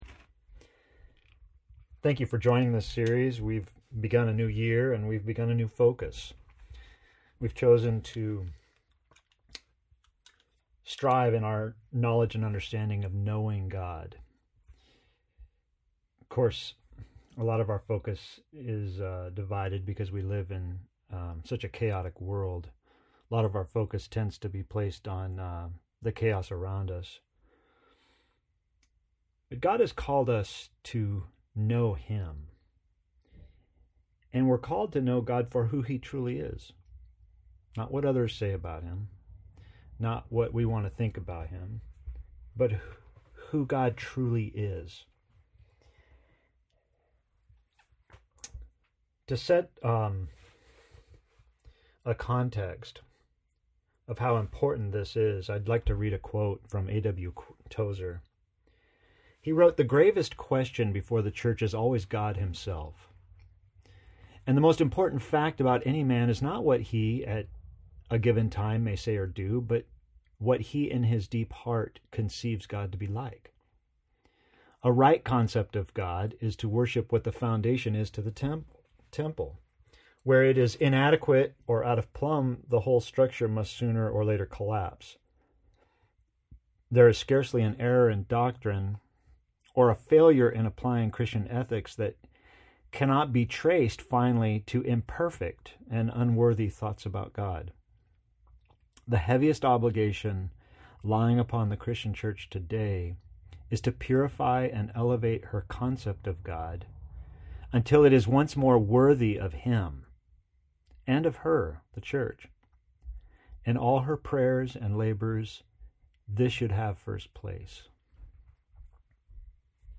Sermon pre-recorded for 2/21/21 AUDIO | VIDEO | TEXT PDF